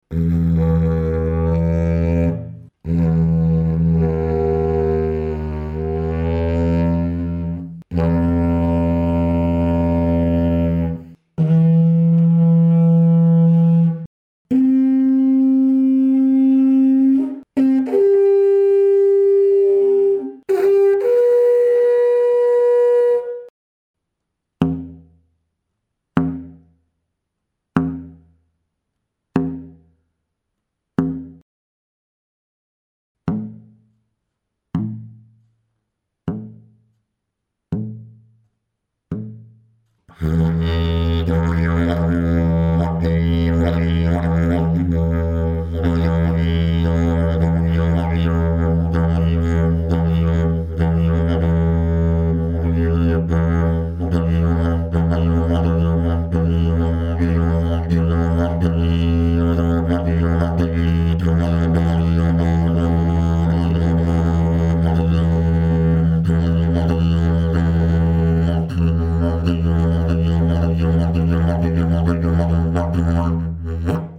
Dg510 ist ein Didgeridoo des Modells 048 gestimmt in E2, mit dem Overblow auf der Oktave E3. Der sehr stabile und leicht zu spielende Grundton hat einen sehr effektiven Resonanzwiderstand.
Die Form erzeugt einen ruhigeren nicht so stark dröhnenden Grundton, der sich gut mit Stimmeffekten modulieren läßt.
The shape produces a calmer, less boomy fundamental tone that can be easily modulated with voice effects.
Fundamental note, draw ranges and overblows at 24� C: E2 � 10 (D#2 to E2 � 50) // E3 � 5 (� 50, � 20) / C4 � 30 / G4 � 20 / Bn4 � 30 Dg510 Technical sound sample 01